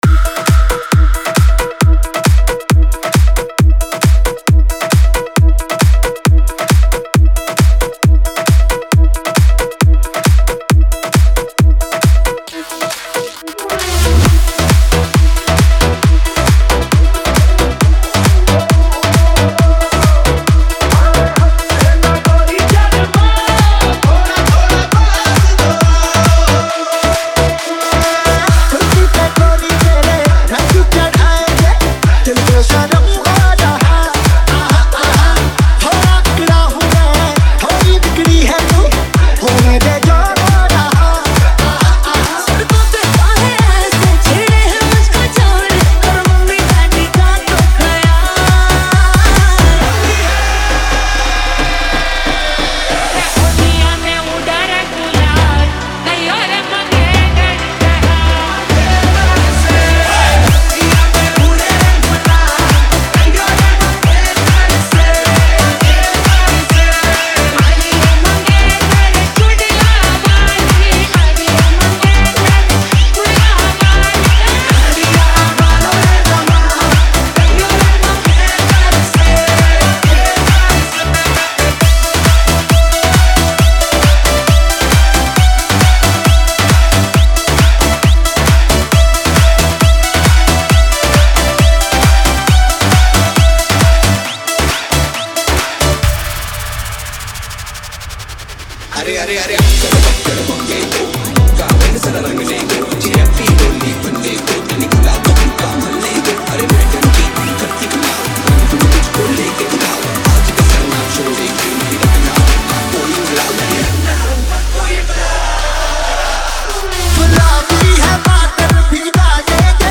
Category : Club